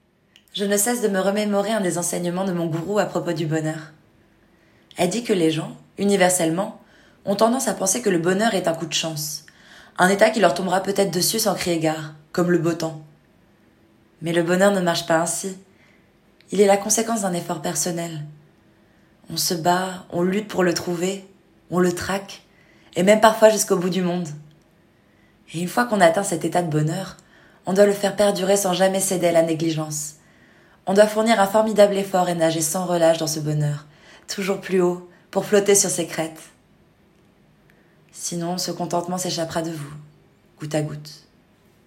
Voix off
5 - 50 ans - Baryton-basse Baryton Ténor